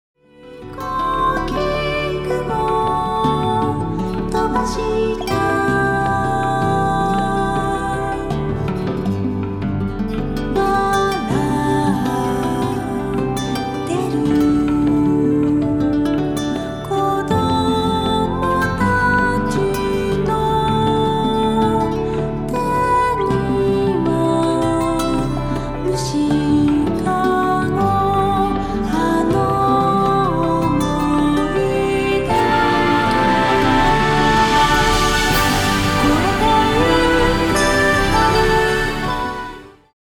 ヴァイオリン
レコーディングスタジオ : JEO